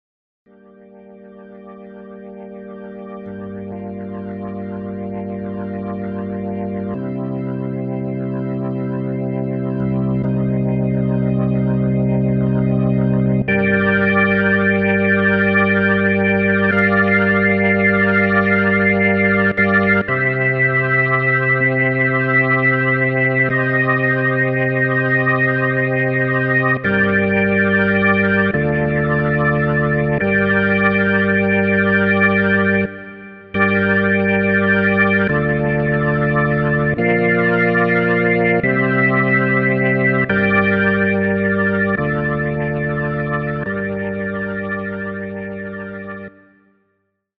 B4 orgona hangja is, bár ez annyira átütő, hogy mindig csínján bánok vele.
b4_organ.mp3